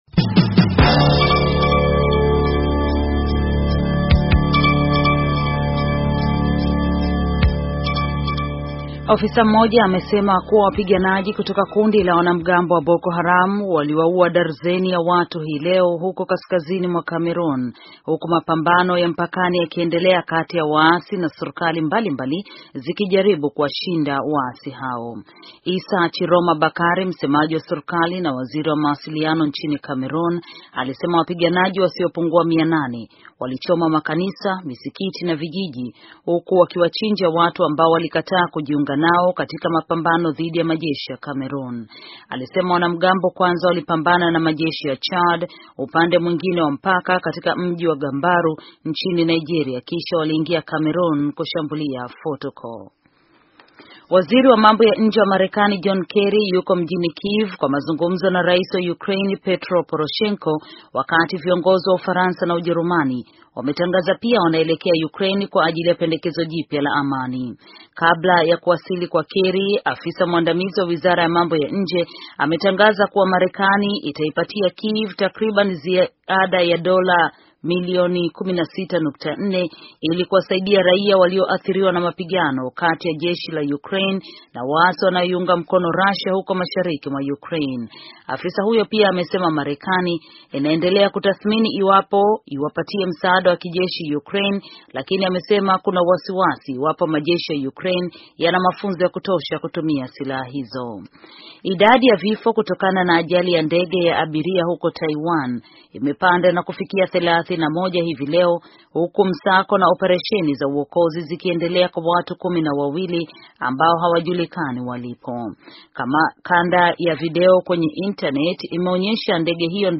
Taarifa ya habari - 5:16